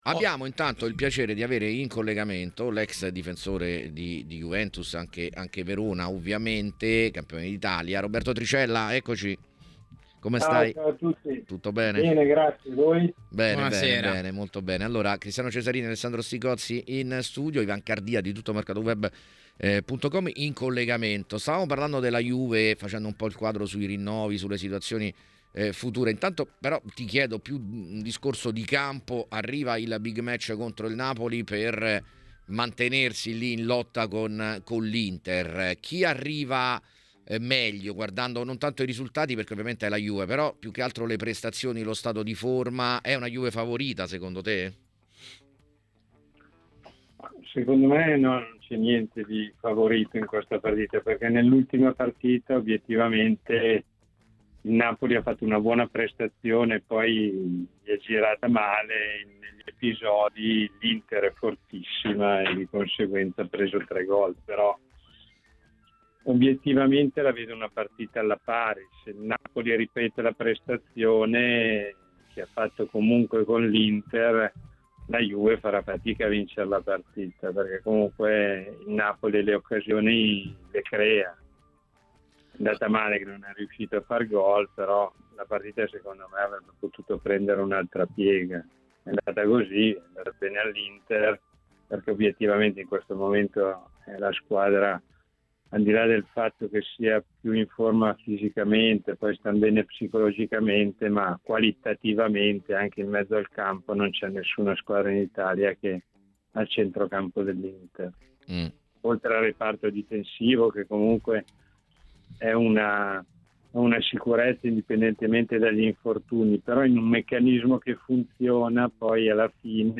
Roberto Tricella, allenatore ed ex calciatore, è intervenuto durante il programma “Piazza Affari” a TMW Radio per commentare vari temi.